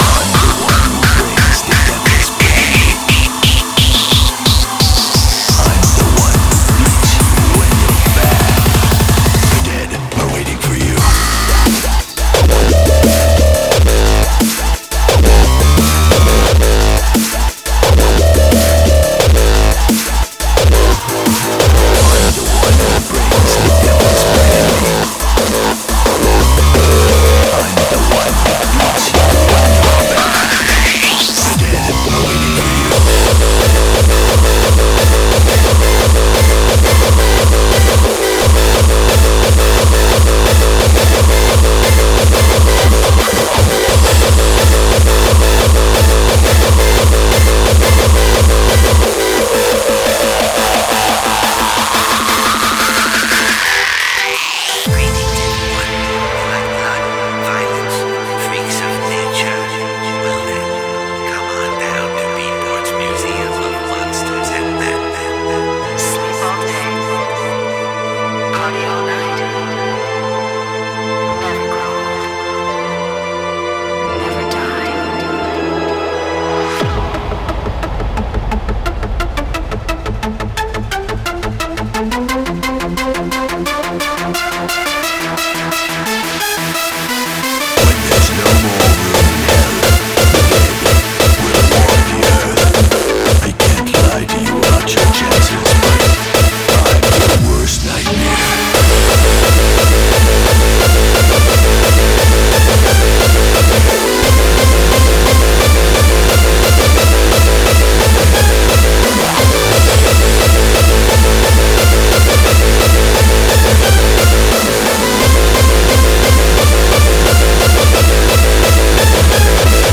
BPM88-175
Audio QualityPerfect (High Quality)
Comments[GABBER]